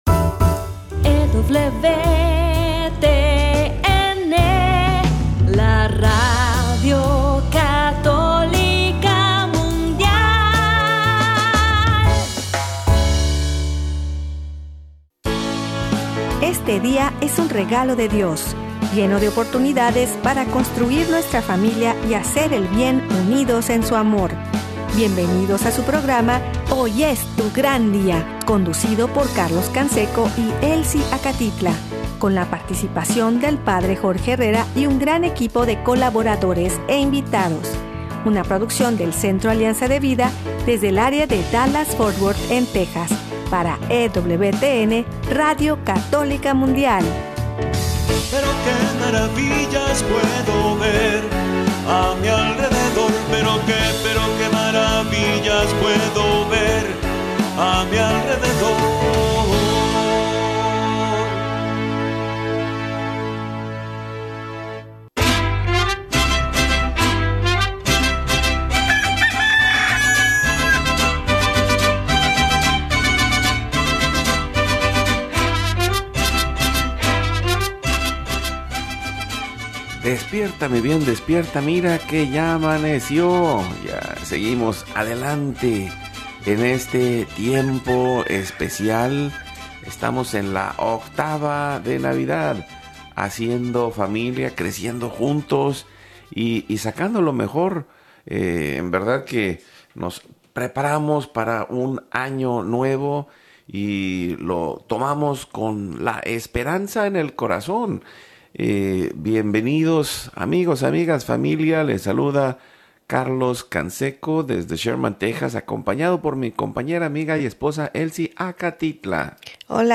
1 Proyecto 2026 55:29 Play Pause 2d ago 55:29 Play Pause Play later Play later Lists Like Liked 55:29 Hoy es tu gran día es un programa de evangelización en vivo desde Dallas, Texas, para vivir en plenitud la vida en Cristo, caminando junto a El para ver las maravillas a nuestro alrededor en la vida diaria como discípulos.